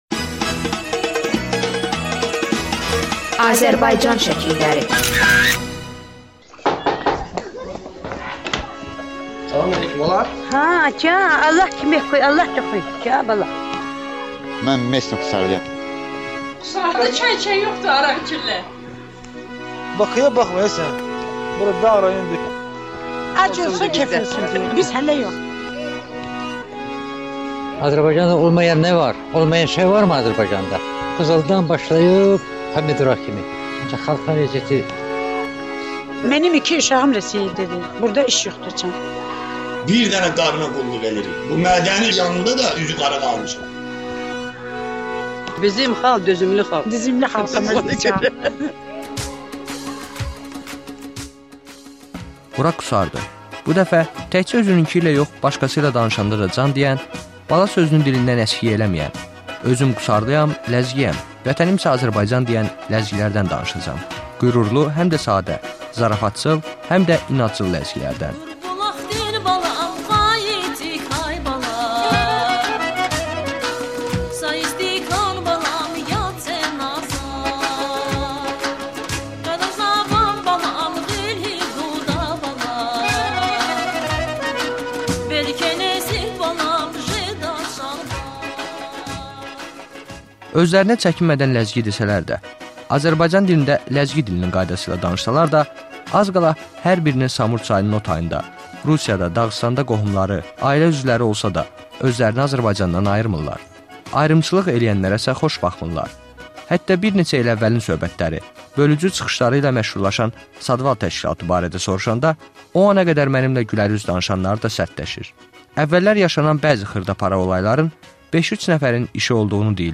reportajı